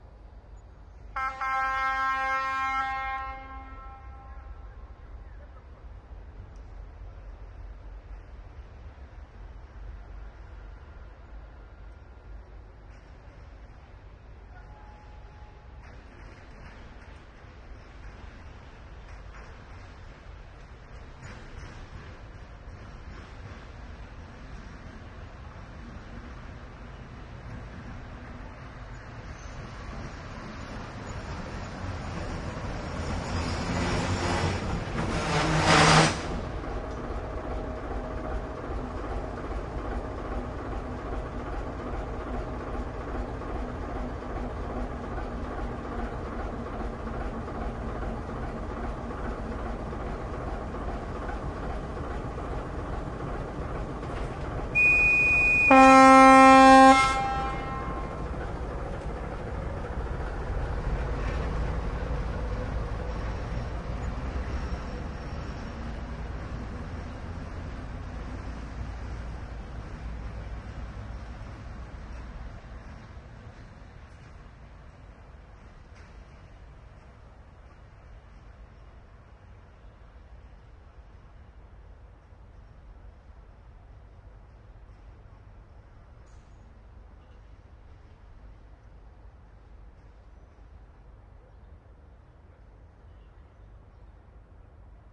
港口/码头的声音 " 船只经过的声音
描述：穿过波浪的拖轮。 用Sennheiser 416录制成Sound Devices 702 Recorder。使用低音滚降来消除隆隆声。在ProTools 10中处理和编辑。 录制于加利福尼亚州玛丽安德尔湾的伯顿查斯公园。
标签： 船舶 码头 海浪 海洋 通行证 旅游 港口 现场录音 交通运输
声道立体声